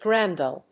/ˈgrænvɪl(米国英語)/